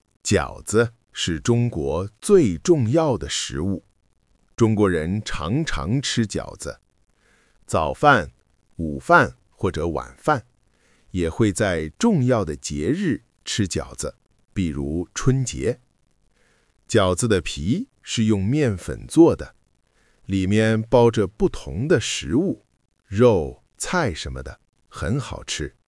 Audio của bài đọc :